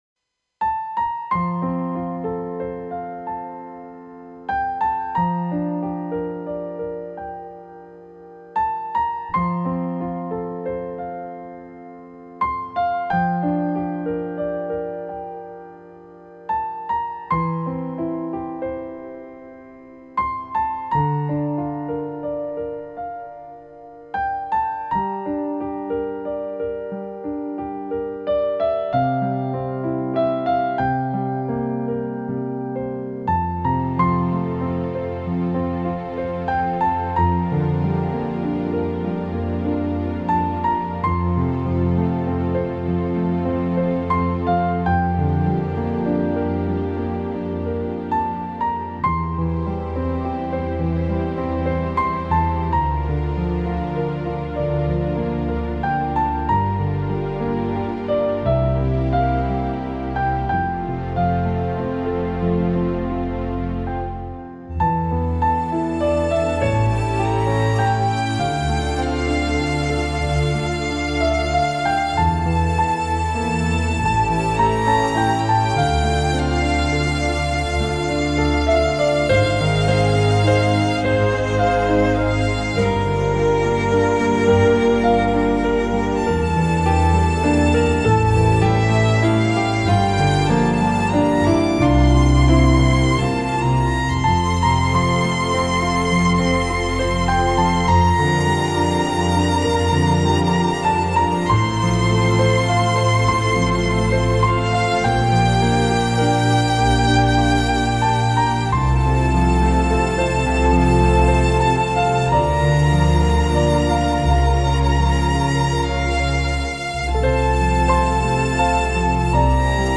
在悠揚的旋律中,細細品味